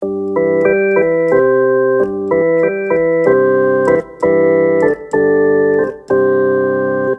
Grön siren
Det finns även en högtalare med möjlighet att sätta på en ljudslinga för att uppmärksamma andra trafikanter på att de behöver flytta på sig.
• Ljudet – får inte påminna om polis, ambulans eller räddningstjänst. Men låter just nu för mycket som glassbilen.